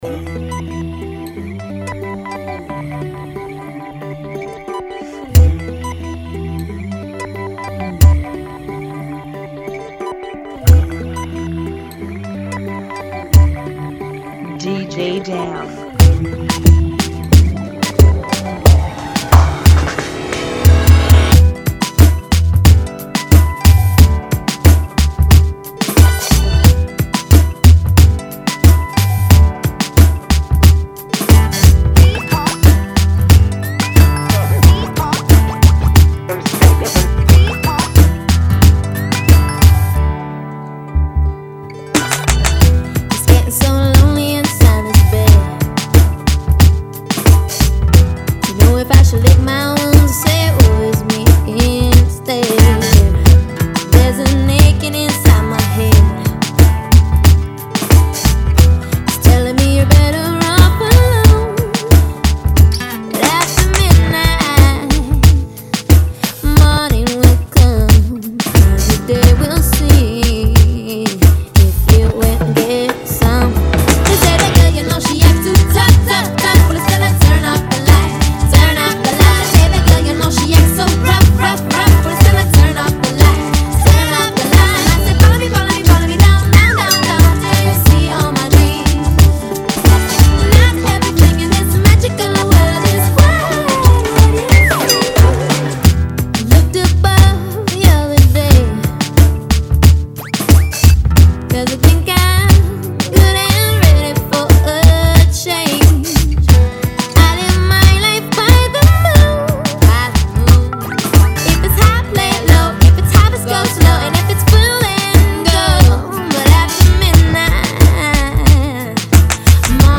(90 BPM)
Genre: Kizomba Remix